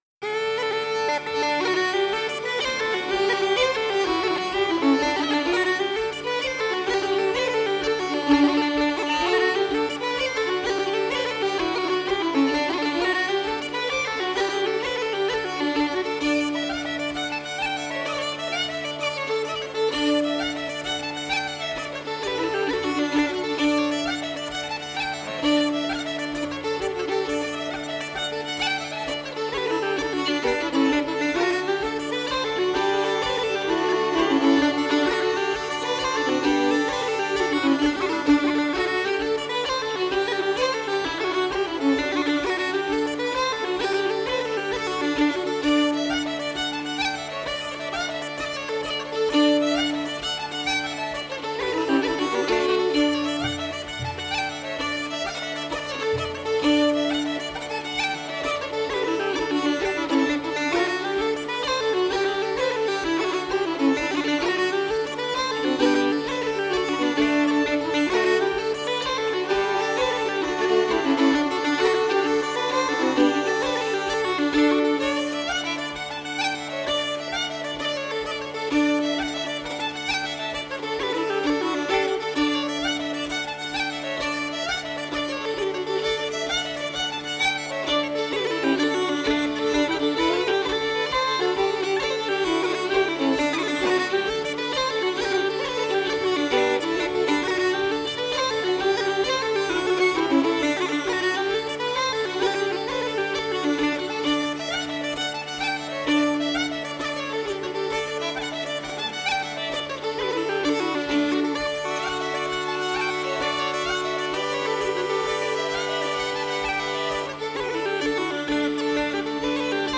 风笛、小提琴等乐器逐渐发展出爱尔兰音乐的固有特色。
幸好，这是一张现场录音的演奏会唱片。